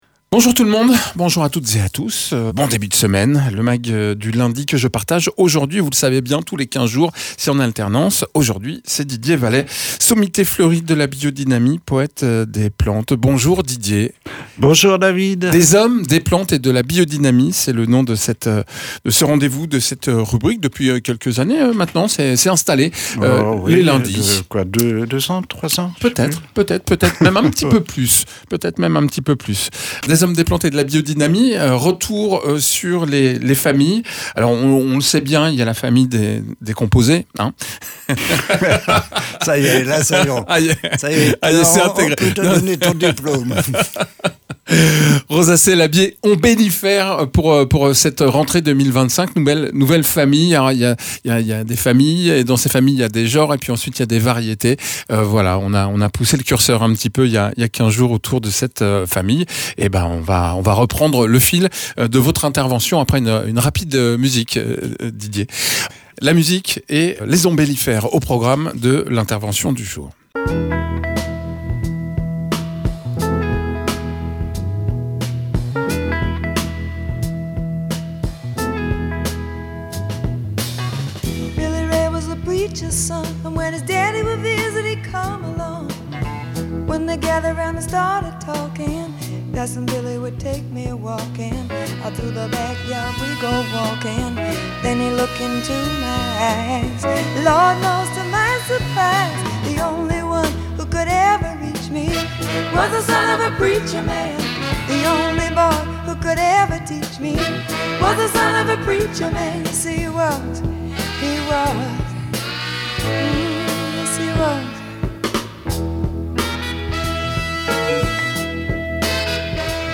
paysan spécialisé dans les plantes aromatiques et médicinales et la biodynamie